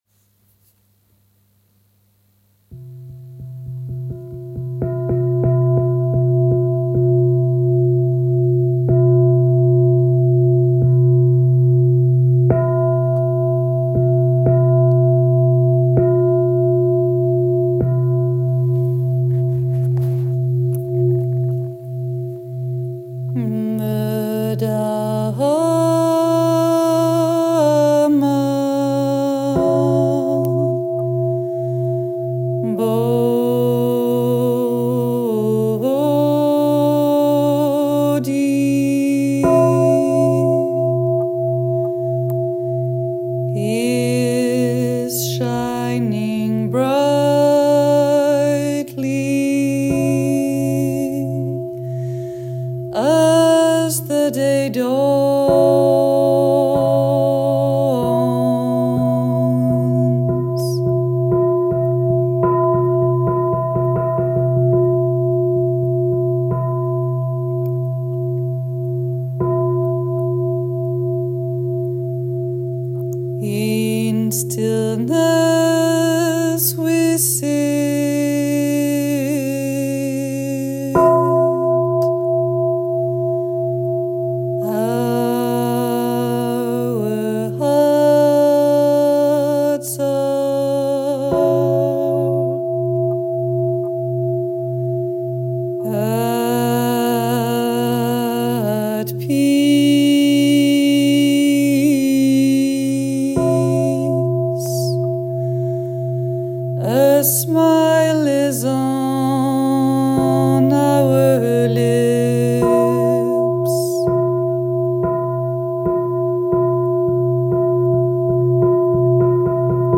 Canto del mattino
morning-chant.m4a